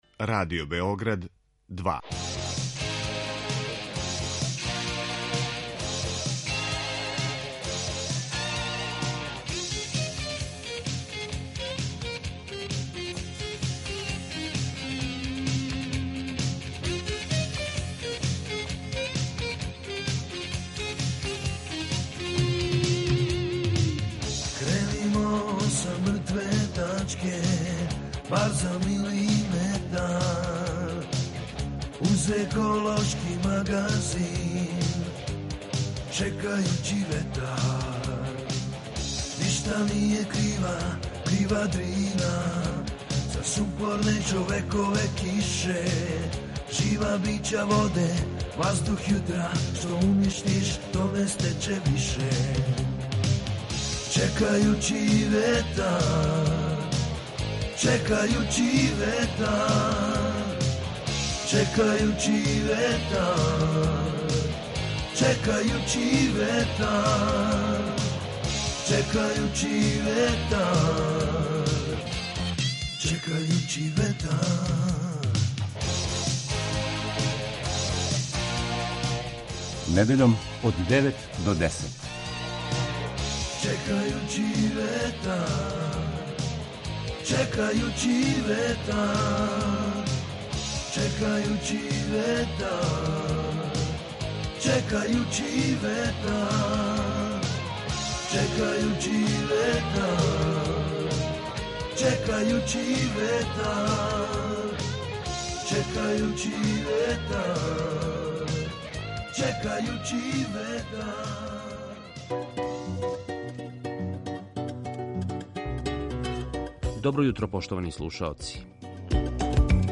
ekološki magazin Radio Beograda 2 koji se bavi odnosom čoveka i životne sredine, čoveka i prirode.